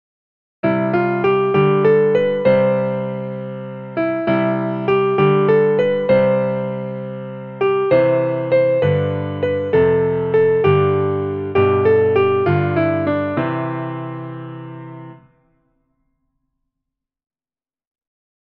a traditional Nursery Rhyme for children
for piano